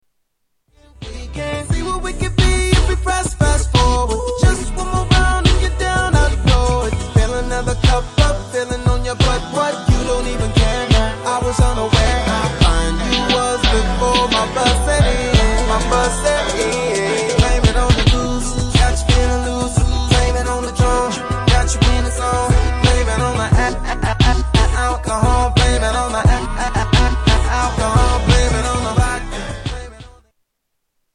AutoTune Songs